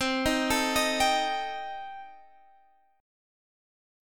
Cm6 Chord
Listen to Cm6 strummed